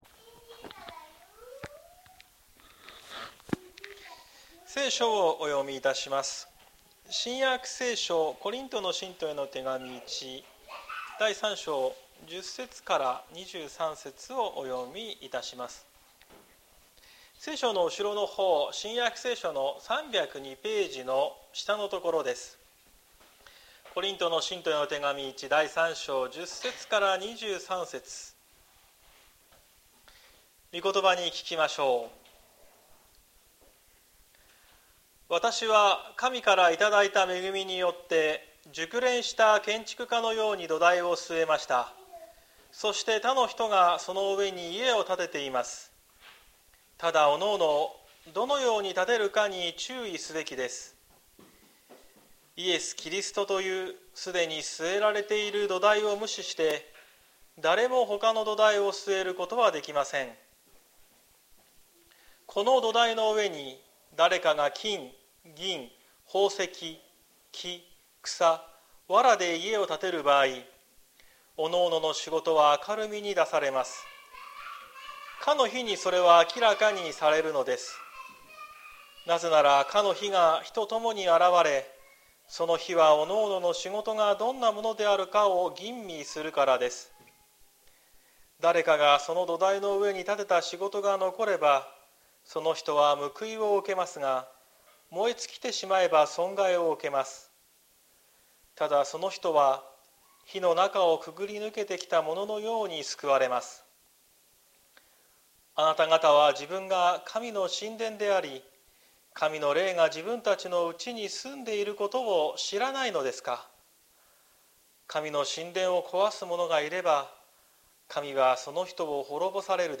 2024年10月13日朝の礼拝「神の神殿」綱島教会
説教アーカイブ。